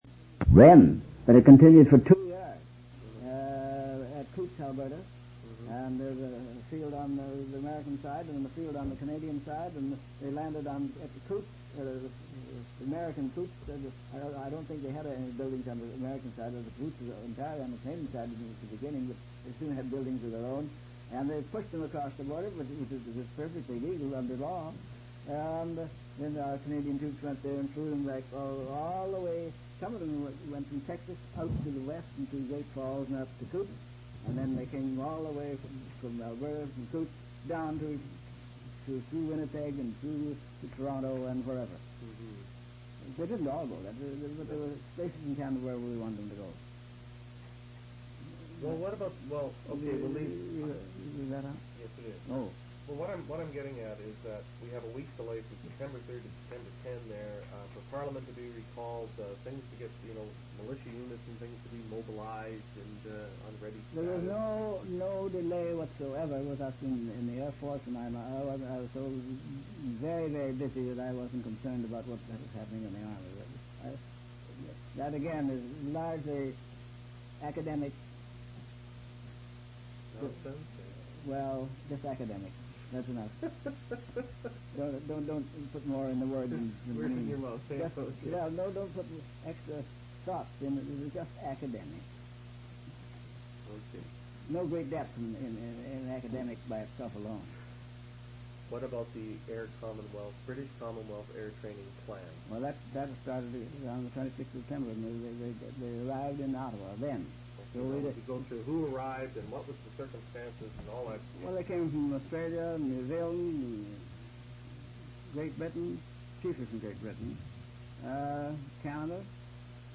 Interview took place on July 27, August 3, 15, 27, 31 and September 12, 1979.